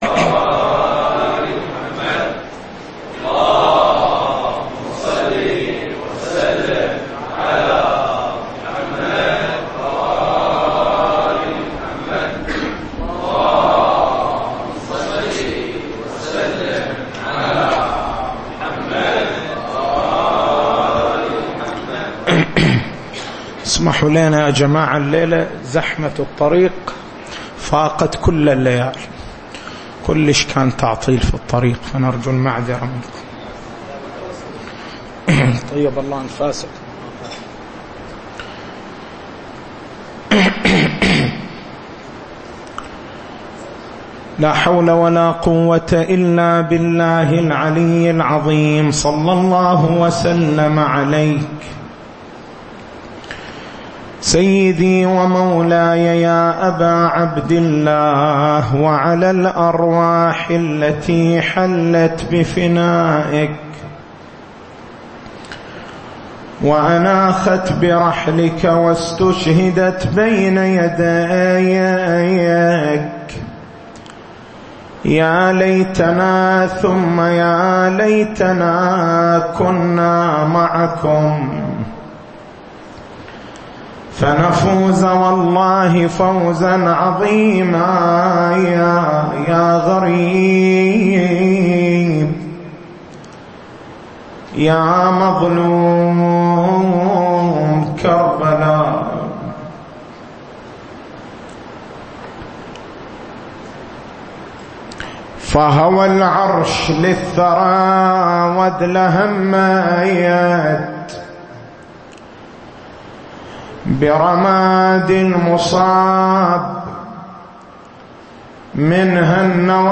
تاريخ المحاضرة: 14/09/1436 نقاط البحث: بيان كيفيّة عمليّة الإحياء هل الإحياء كلّي أم جزئيّ؟ بيان حال الناس عند عمليّة الإحياء التسجيل الصوتي: اليوتيوب: شبكة الضياء > مكتبة المحاضرات > شهر رمضان المبارك > 1436